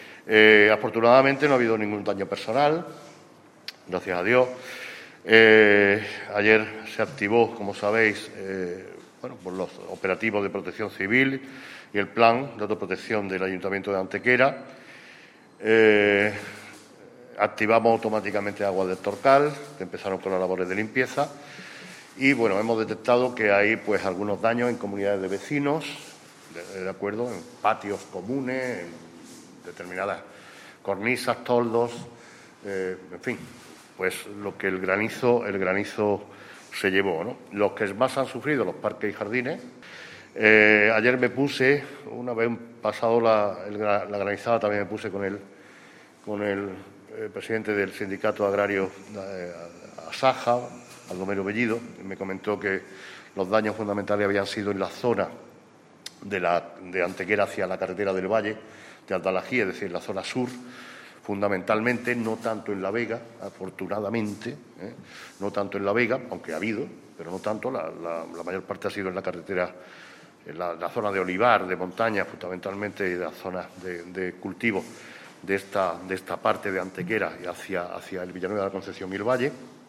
El alcalde de Antequera, Manolo Barón, ha comparecido en la mañana de hoy ante los medios de comunicación para realizar una primera valoración oficial sobre los efectos de la tormenta de granizo que, literalmente, "azotó" a nuestra ciudad en la tarde de ayer en lo que el propio Alcalde calificaba como "la mayor granizada del siglo".
Cortes de voz